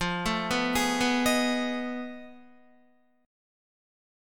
F7#9b5 Chord
Listen to F7#9b5 strummed